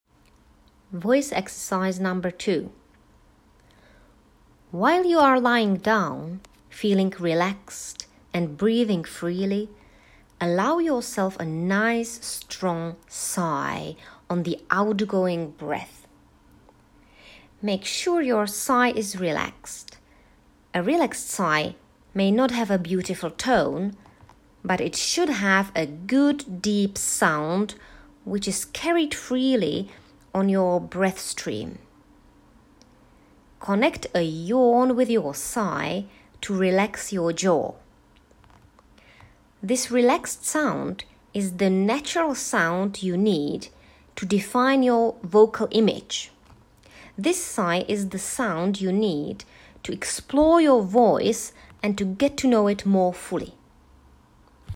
voice_exercise_2.m4a